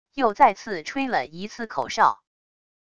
又再次吹了一次口哨wav音频